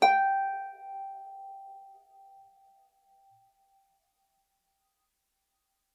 KSHarp_G5_mf.wav